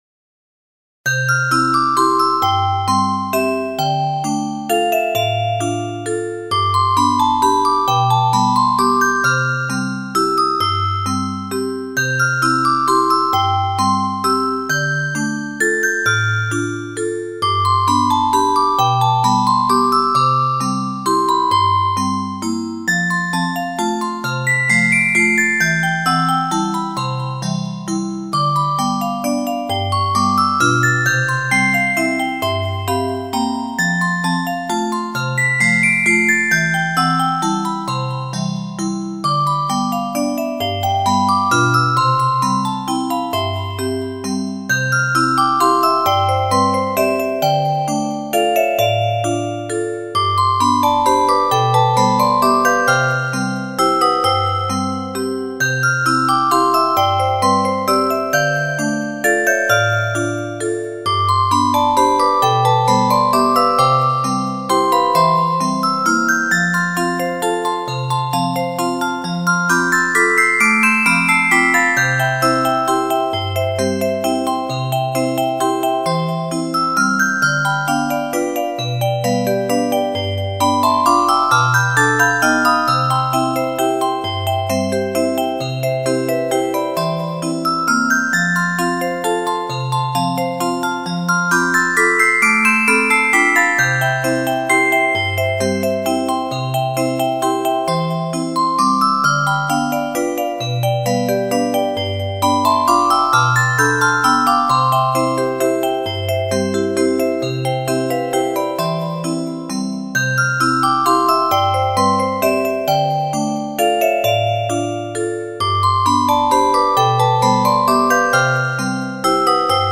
スローテンポロング明るい穏やか